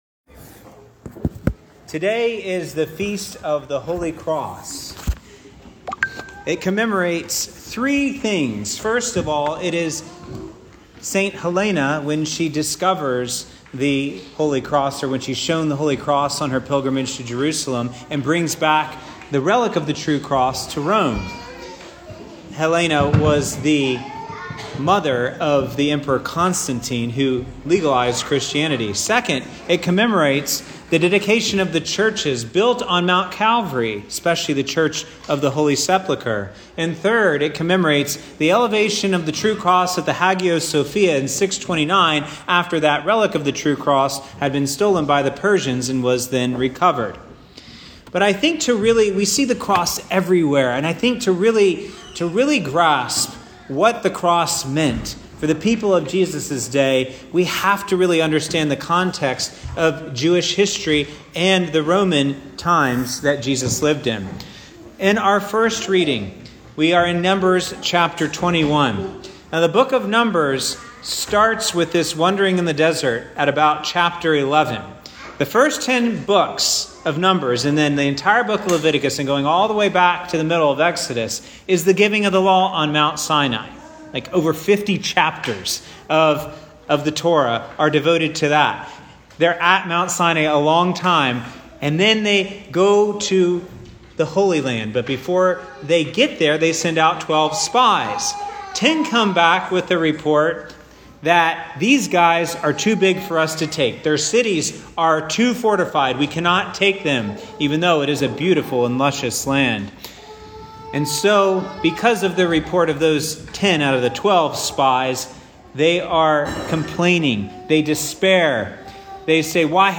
Roodmas - The Feast of the Exaltation of the Holy Cross Click below to hear Father's homily.